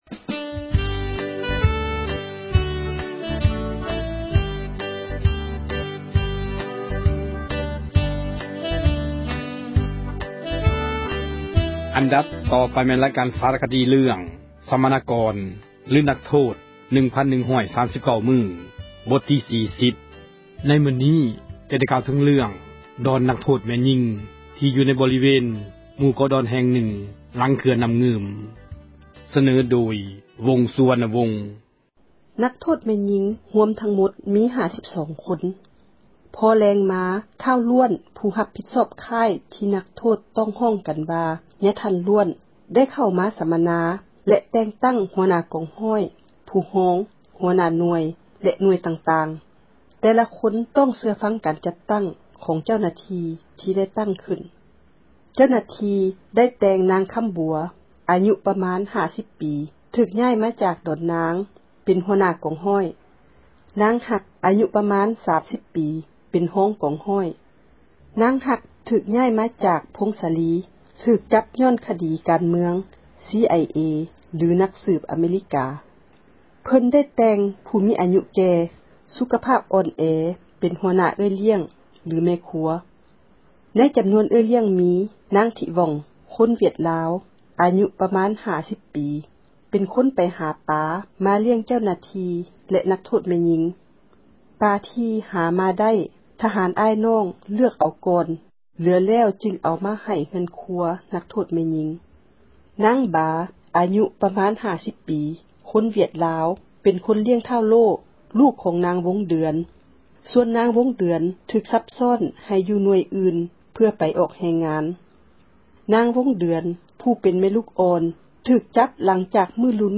ຣາຍການ ສາຣະຄະດີ ເຣື້ອງ ສັມມະນາກອນ ຫຼື ນັກໂທດ 1,139 ມື້ ບົດທີ 40. ໃນມື້ນີ້ ຈະໄດ້ ກ່າວເຖິງ ເຣື້ອງ ດອນນັກໂທດ ແມ່ຍິງ ຊຶ່ງຢູ່ໃນ ບໍຣິເວນ ໝູ່ເກາະ ດອນຫລັງ ເຂື່ອນ ນ້ຳງື່ມ. ສເນີໂດຍ